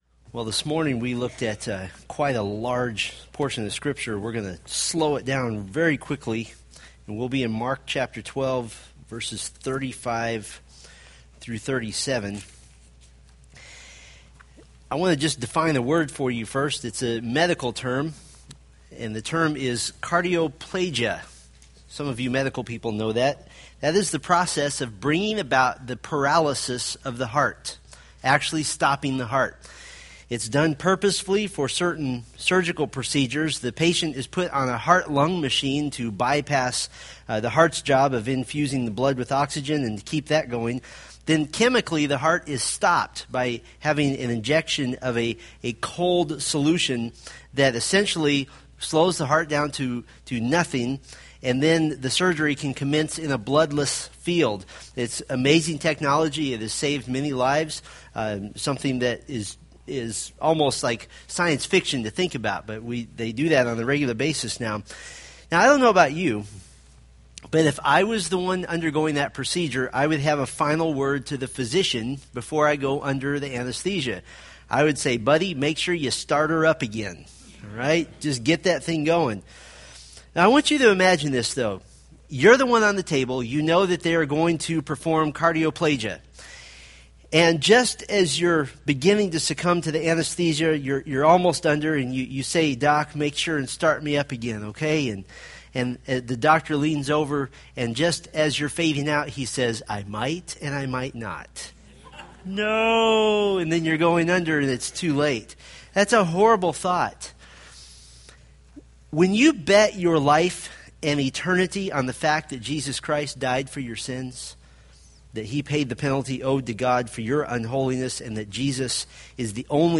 Mark Sermon Series